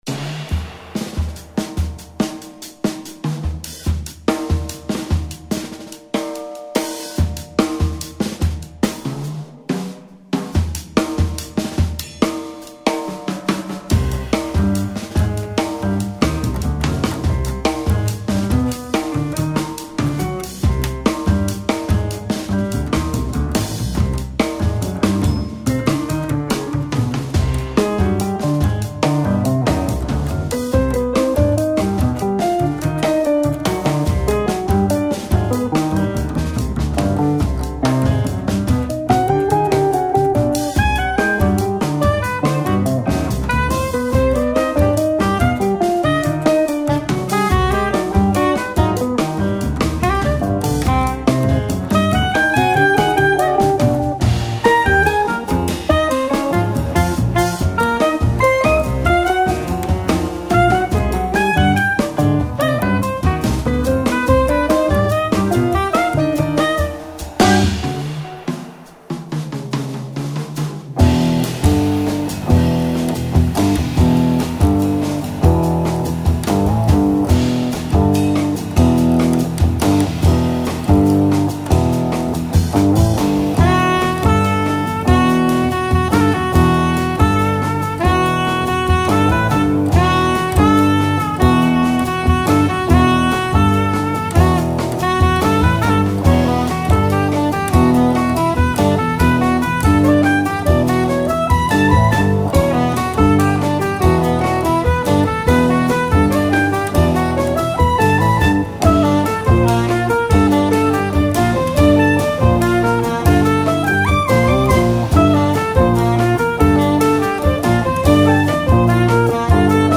guitars
soprano and tenor saxophones
piano, rhodes, accordeon
bass
drums
Jazz